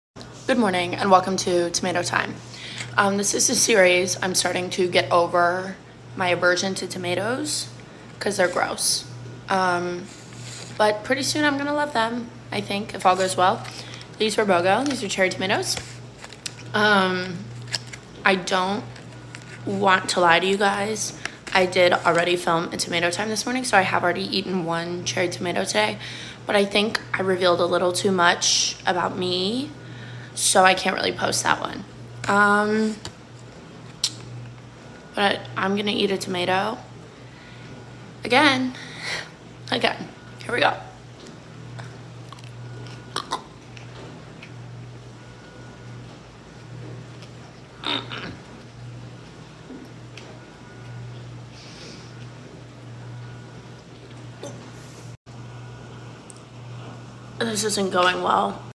TW: gross eating noises welcome sound effects free download